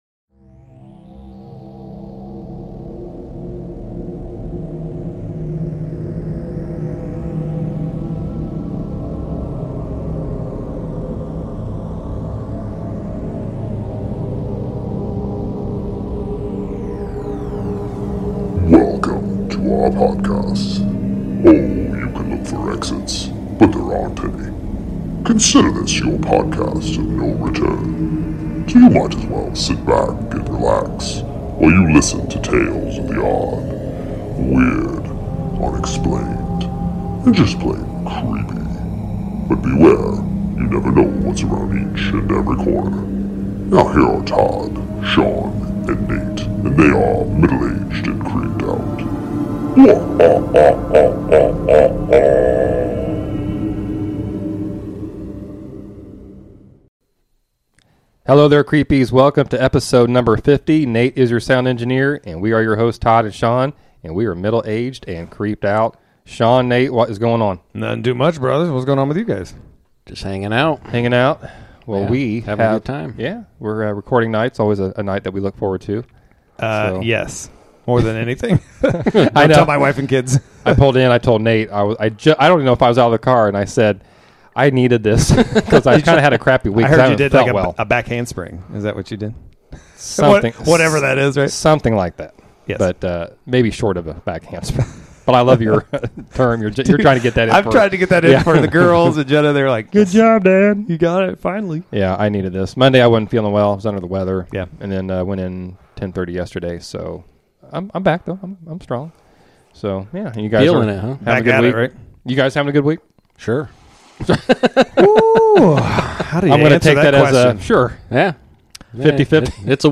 The guys celebrate episode #50 and have an in studio, creepy sit-down discussion with listener (and friend of the show)